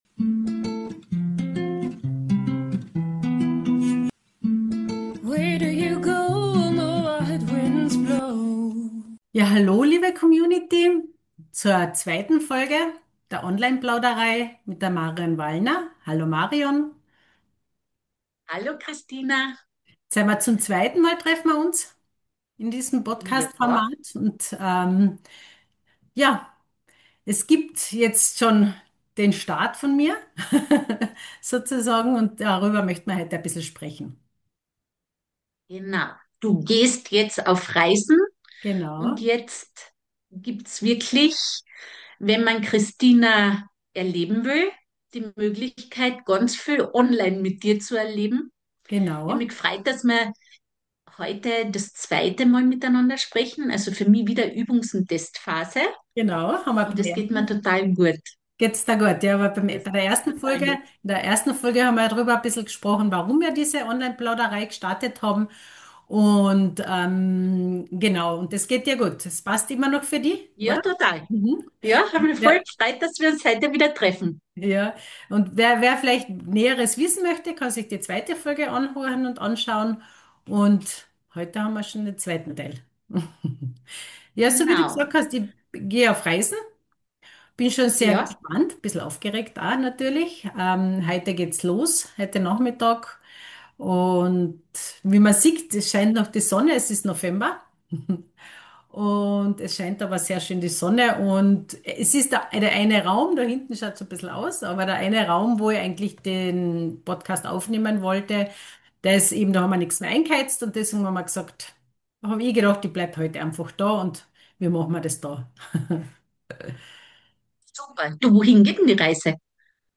Beschreibung vor 4 Monaten Willkommen zurück zur Online Plauderei – deinem Raum für inspirierende Gespräche, persönliche Entwicklung und energetische Arbeit im Online-Zeitalter.